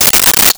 Sword Swipe 02
Sword Swipe 02.wav